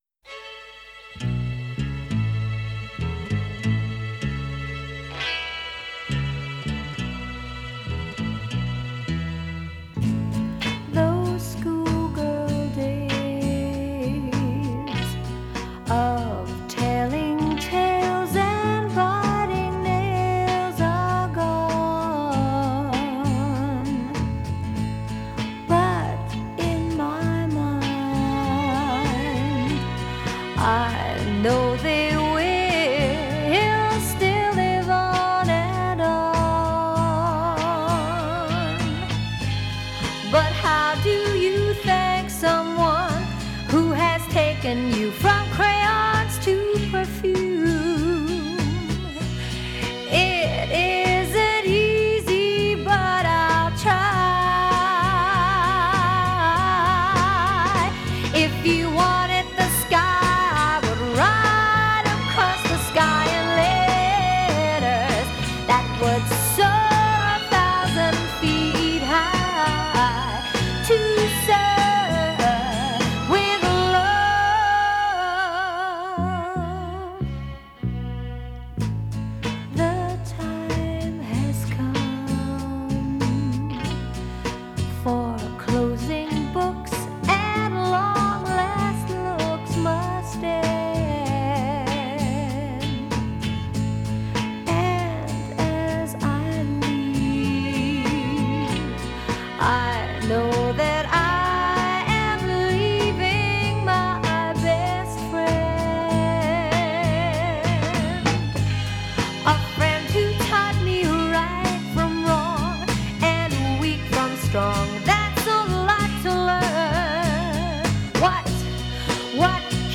She both appeared in the film and sang the title song.